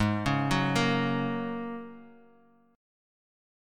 Ab7sus2sus4 chord